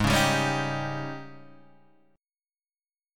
G# Major 7th Suspended 4th